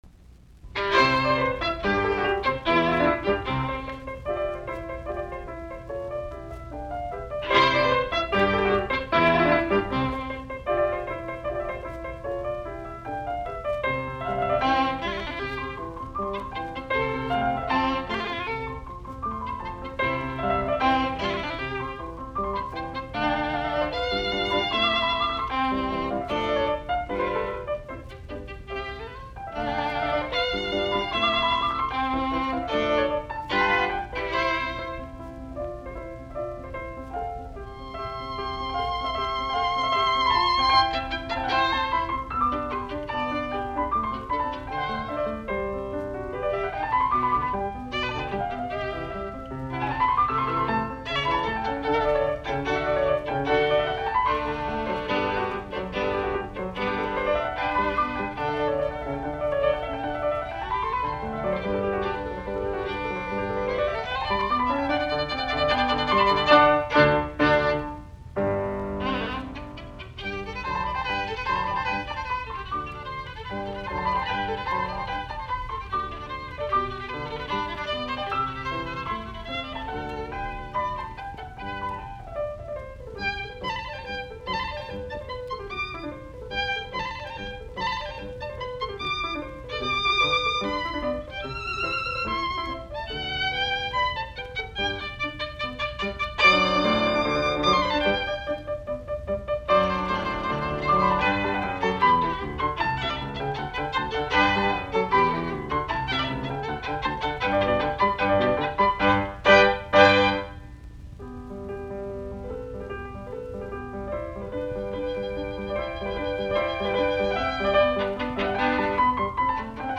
Soitinnus: Viulu, piano.